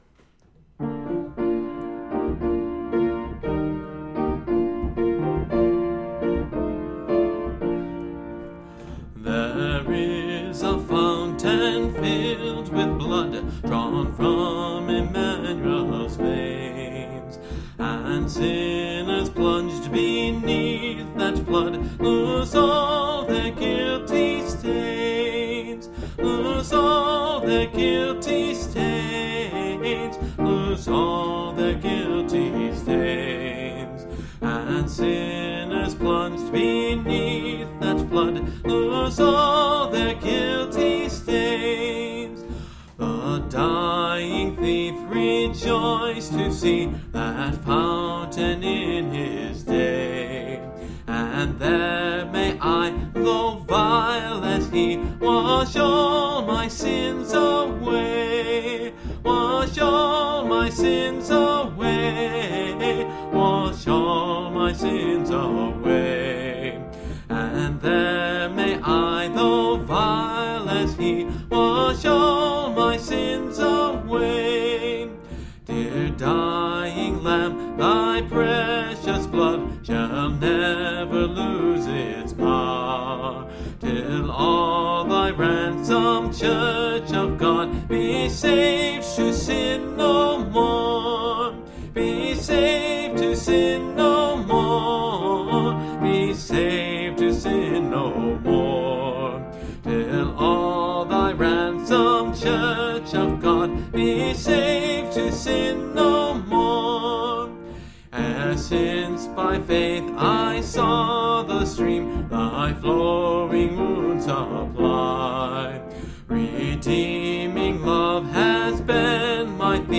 This is definitely a classic hymn.  It doesn’t have a chorus, but each verse has some ending lines that do a repetitive amplification of the thought.